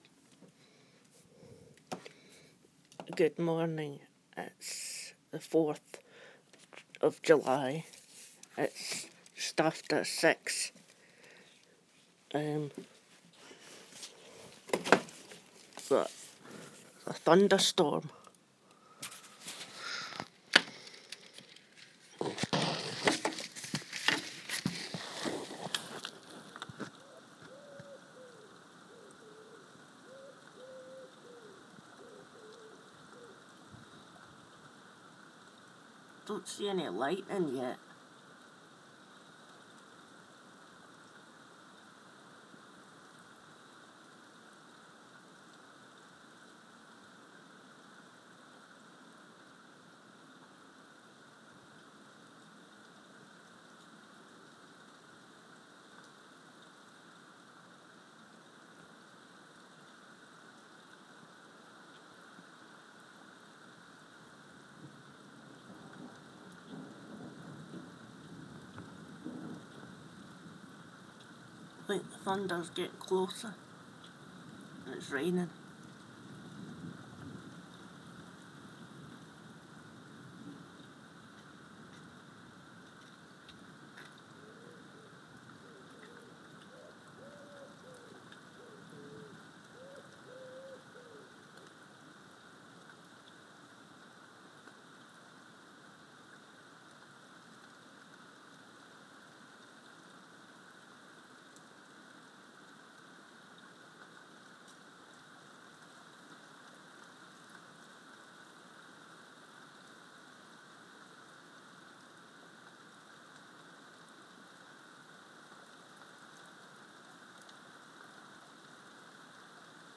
a thunder storm